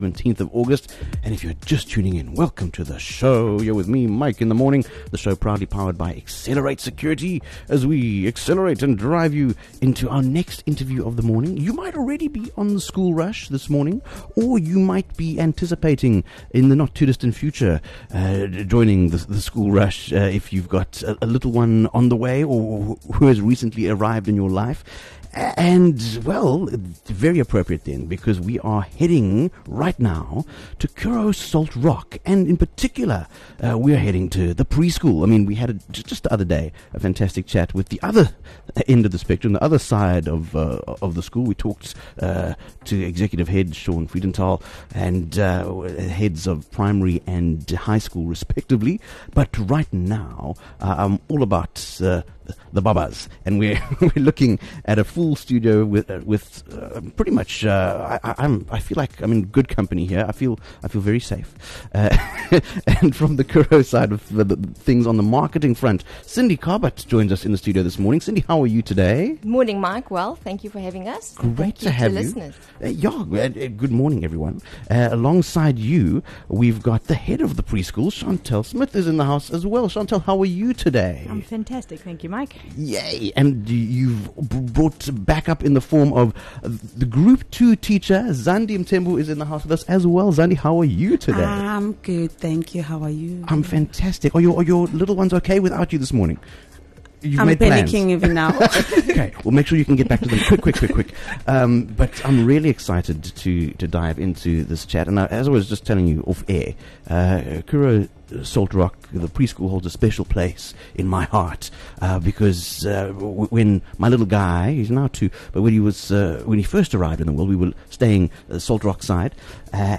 chatting to three of its representatives